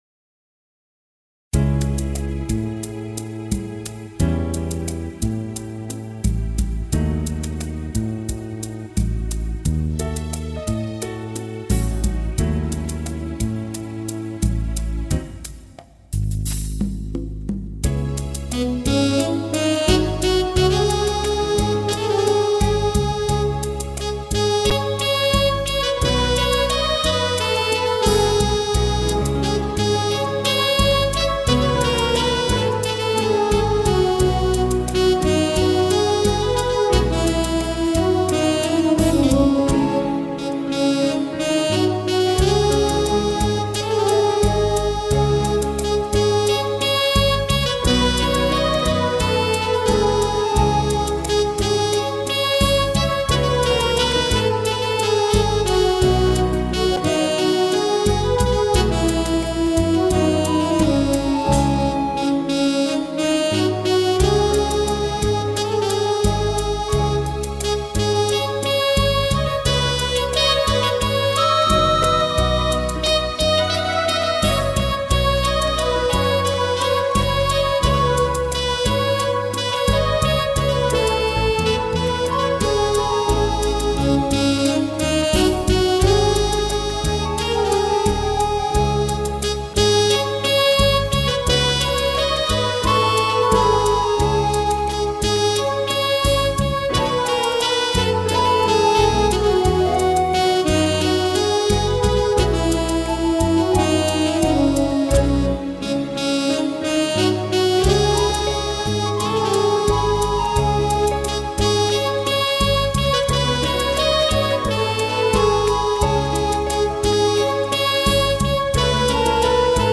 INSTRUMENTAL Section